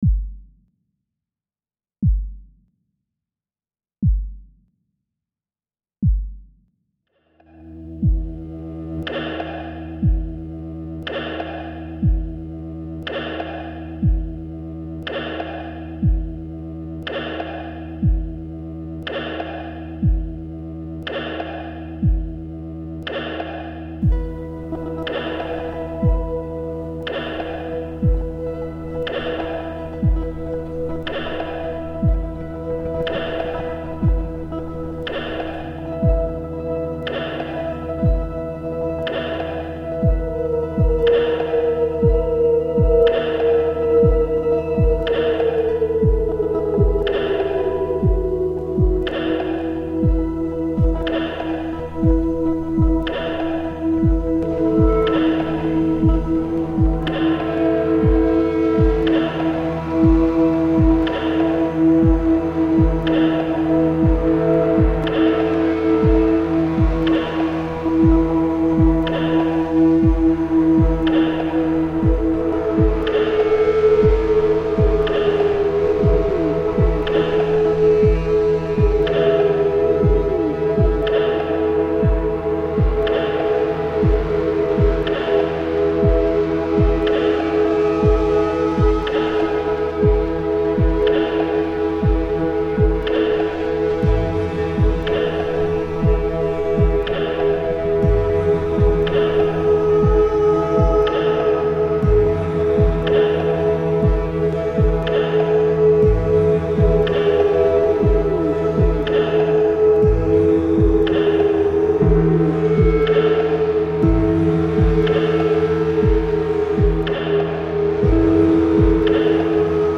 guitar, vocals, drum